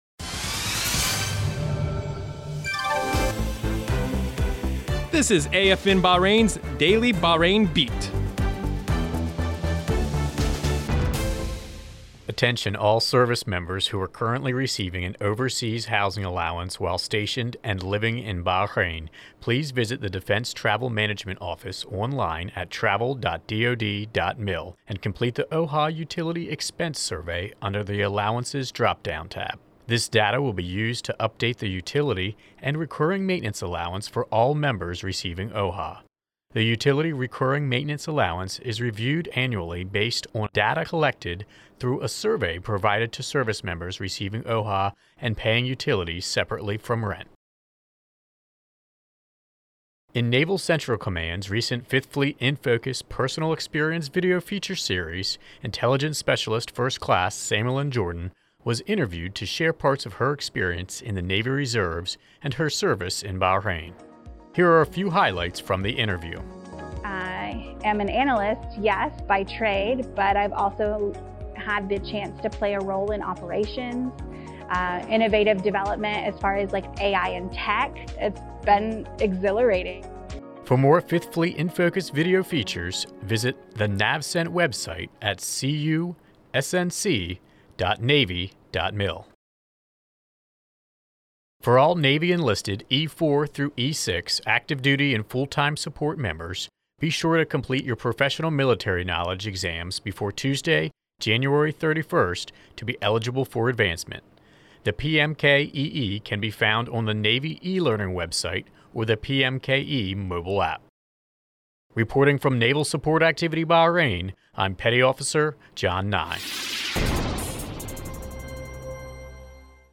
Two-minute newscast covering the Overseas housing Allowance Survey, 5th Fleet Infocus Interview, and the Navy PMKEE Deadline.